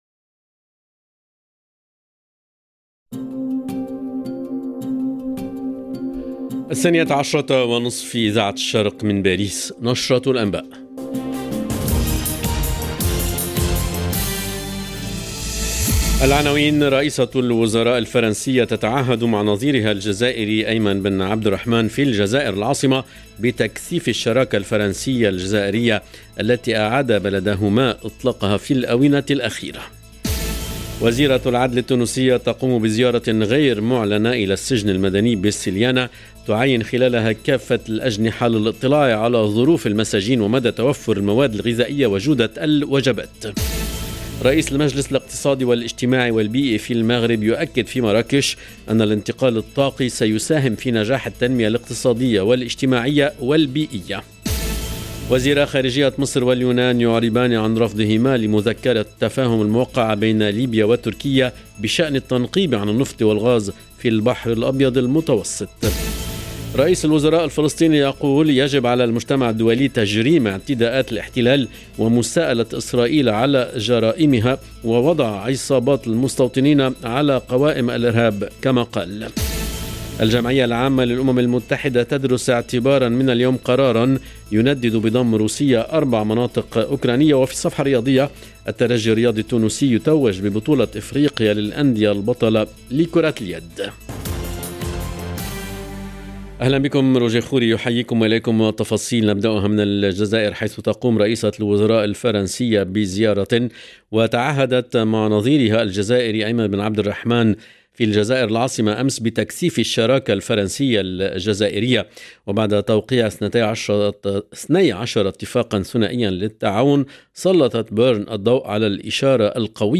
LE JOURNAL DE 12H30 EN LANGUE ARABE DU 10/10/22